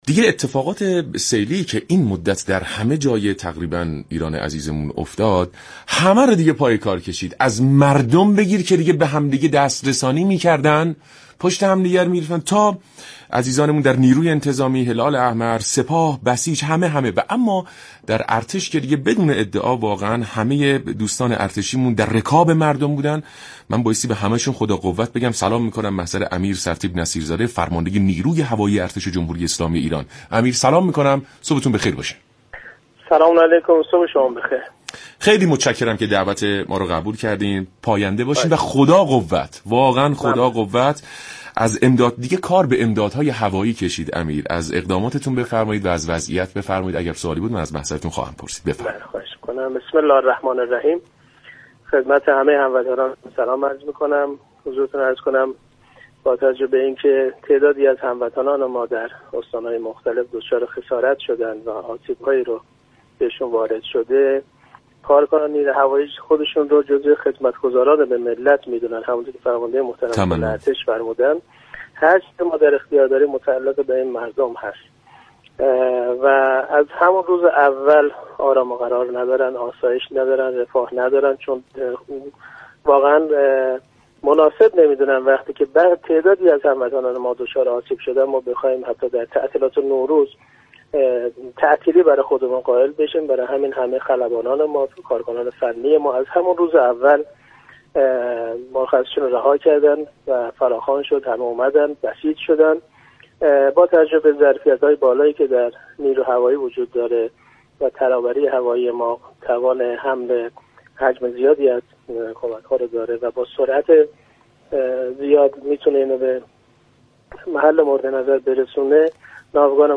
امیر سرتیپ عزیز نصیرزاده فرمانده نیروی هوایی ارتش جمهوری اسلامی ایران در برنامه سلام صبح بخیر رادیو ایران گفت : بالغ بر 200 تن بار را به اهواز و خرم آباد ارسال كردیم